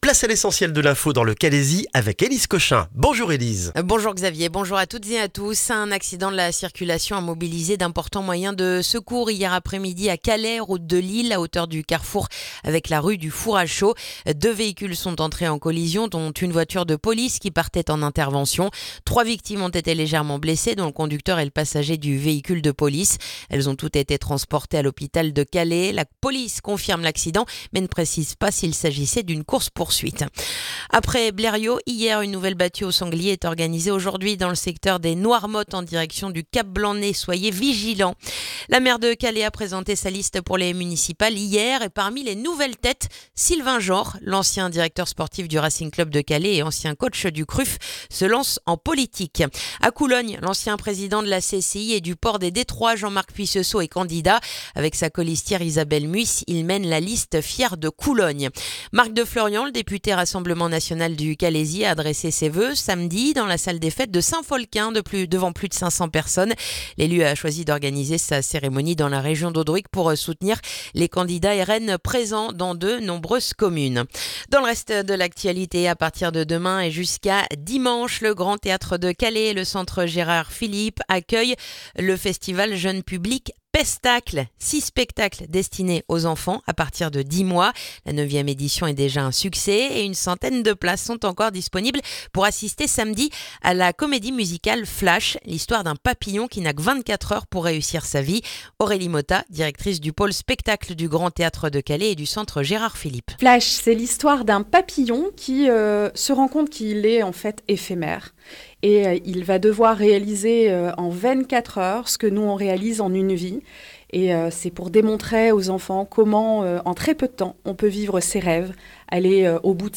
Le journal du mardi 3 février dans le calaisis